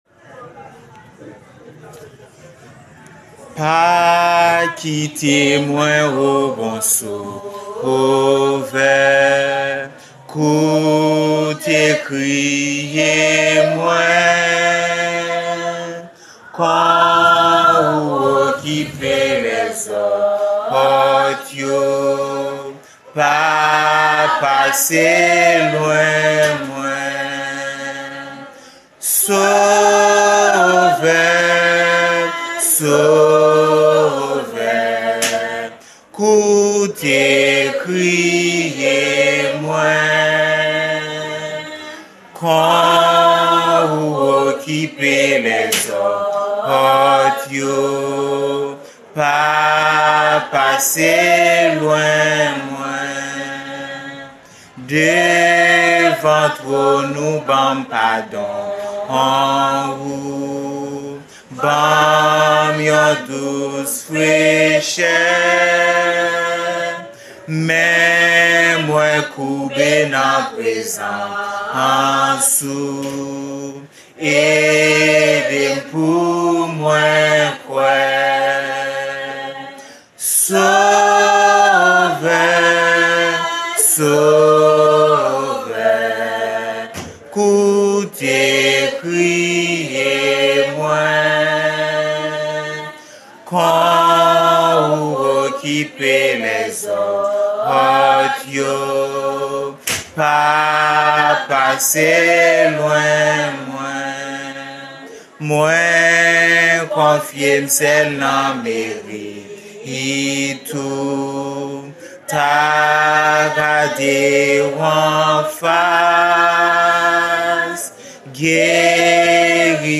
Kreyol song – Pa kite mwen, O bon Sovè! (Pass Me Not, O Gentle Savior)